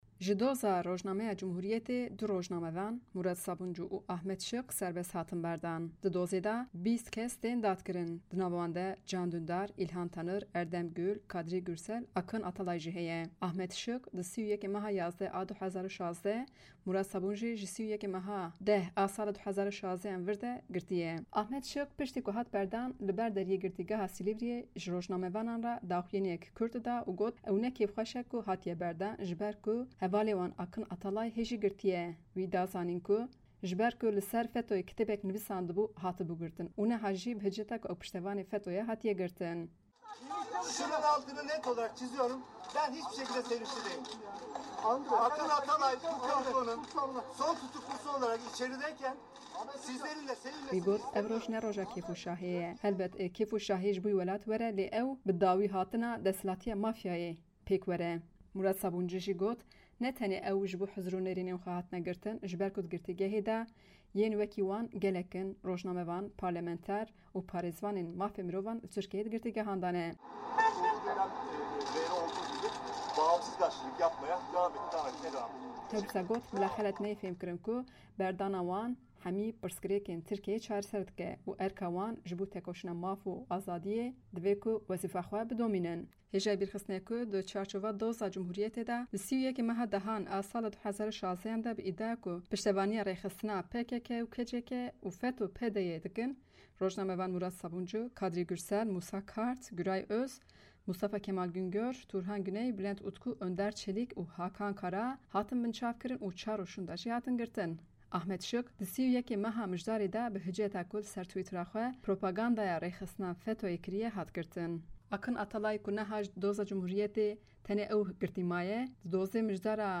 Murat Sabuncu piştî derketina ji zîndanê